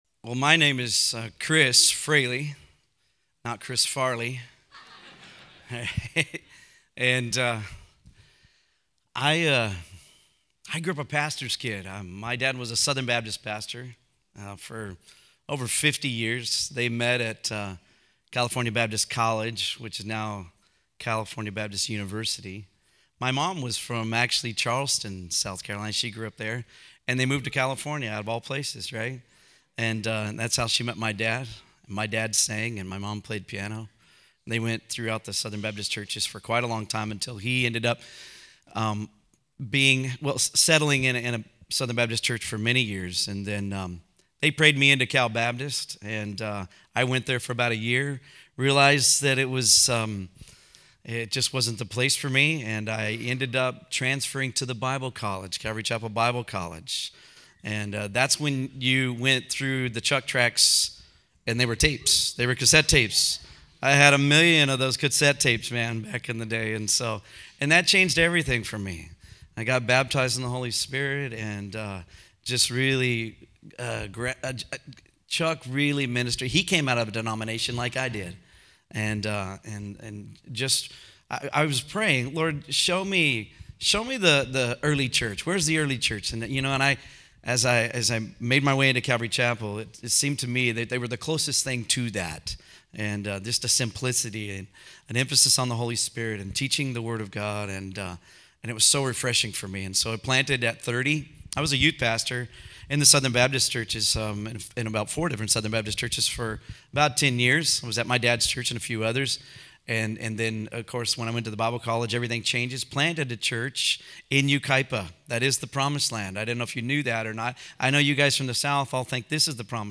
Home » Sermons » 10 Keys to Working with Your Pastor
Conference: Worship Conference